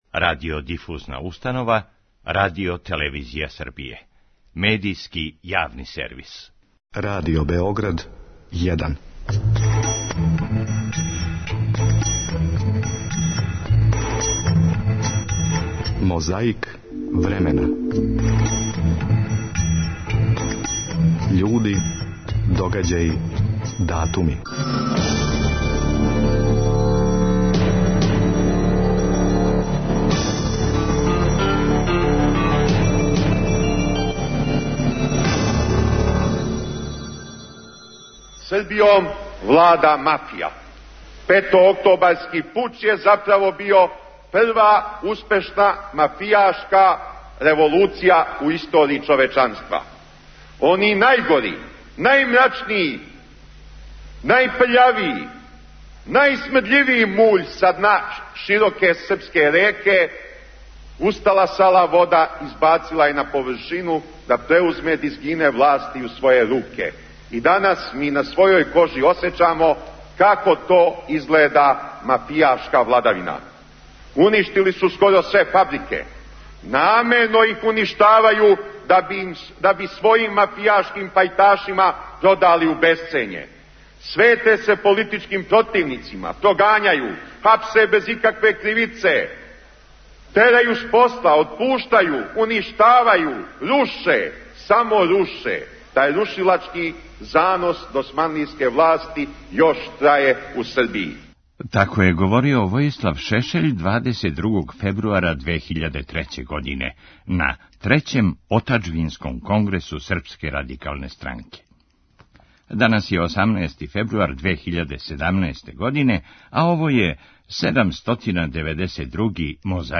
Војислав Шешељ обавестио је присталице о свом добровољном одласку у Хаг, на Трећем Отаџбинском конгресу Српске Радикалне странке, 22. фебруара 2003. године.
У специјалној емисији „О домаћим издајницима" 18. фебруара 1973. године чуле су се изјаве Милана Недића, председника српске владе у периоду Нацистичке окупације и Краља Петра II Карађорђевића.
Ево шта је рекла о редитељу Емиру Кустурици славна глумица Катрин Денев на конференцији за новинаре у Центру „Сава" 2005. године.